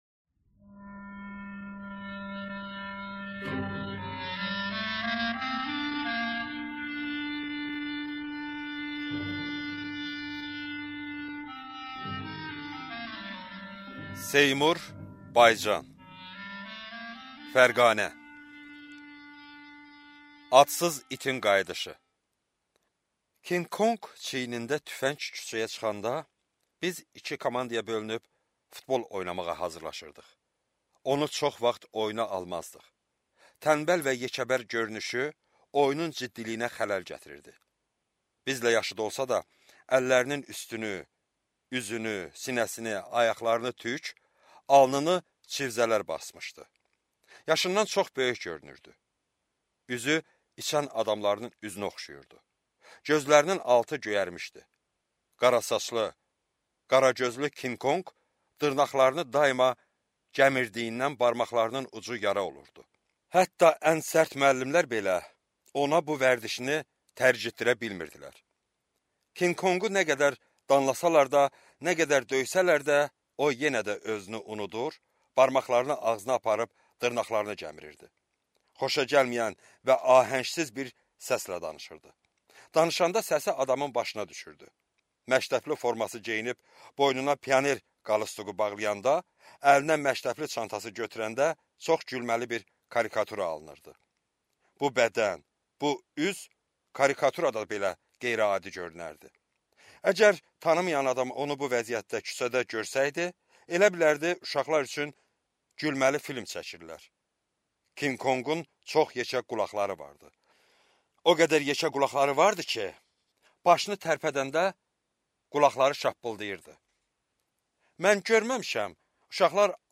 Аудиокнига Fərqanə | Библиотека аудиокниг